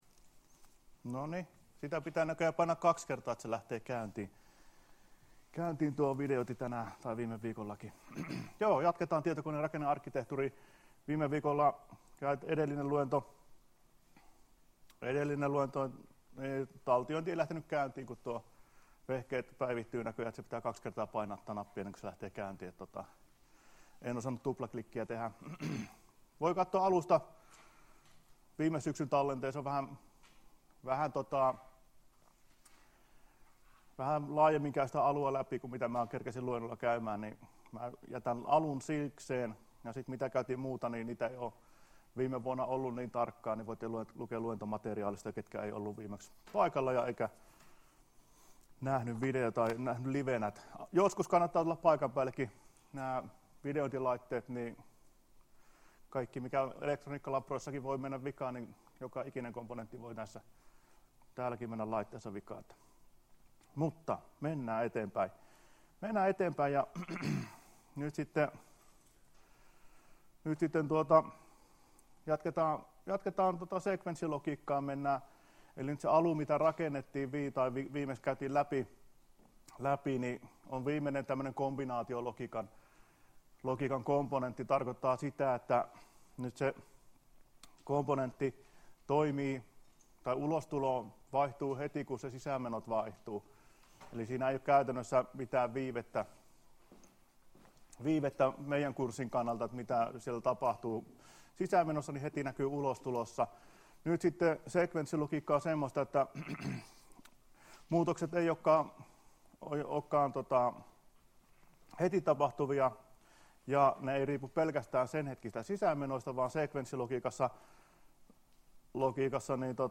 Luento 4.10.2016 — Moniviestin